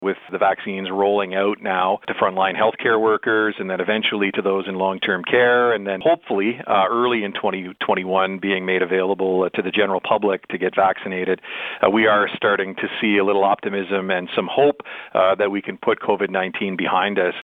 Smith praised retired General Rick Hillier in a recent interview with Quinte News and says while there is some reliance on the Federal Government to acquire the vaccines, Hillier and his team have a firm plan in place to get those doses out to the people of Ontario.